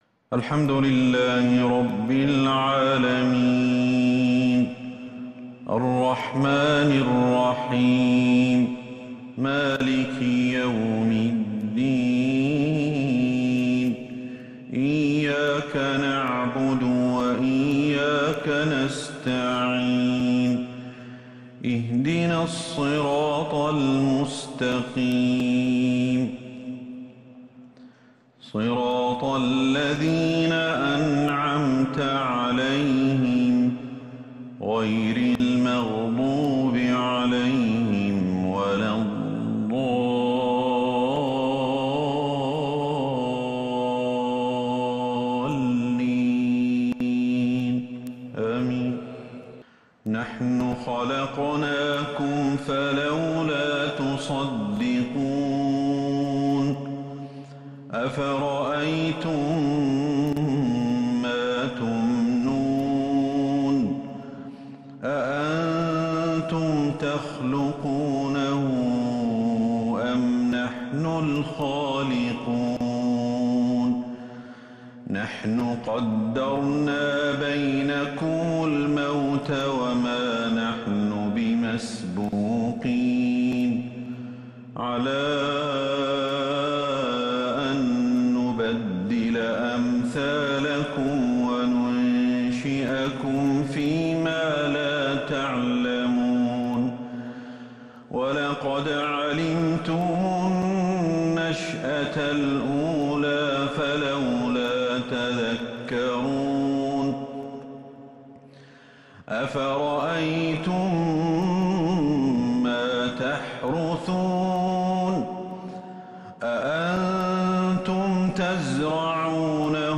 عشاء السبت 6 محرم 1443 هـ آواخر سورة {الواقعة} > 1443 هـ > الفروض